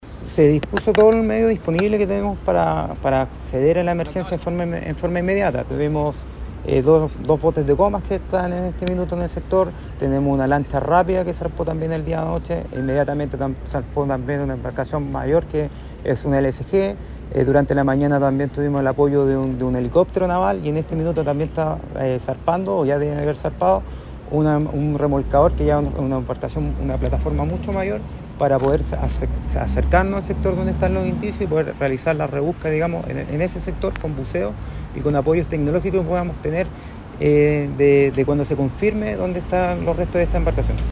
Díaz detalló los recursos que se pusieron a disposición en las primeras horas de búsqueda.